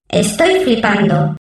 voz nș 0136